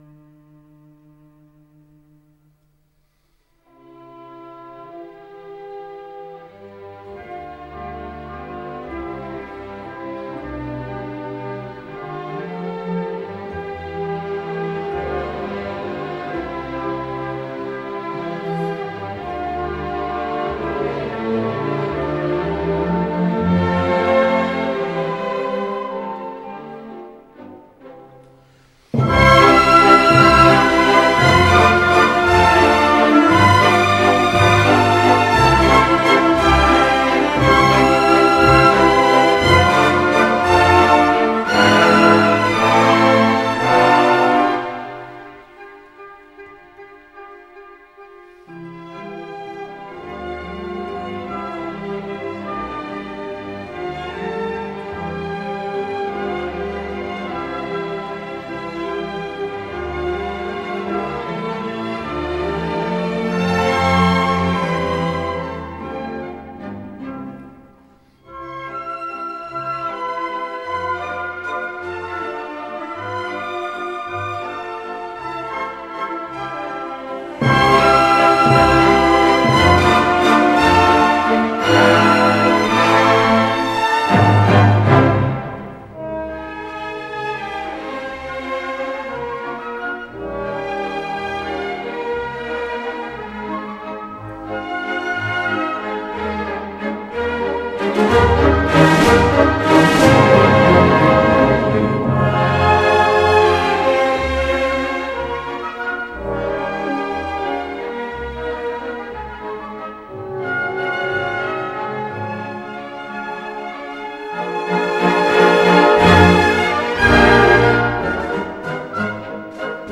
EMPERIOR-WALTZ-BERLIN-PHILAMONIC-ORCHESTRA.wav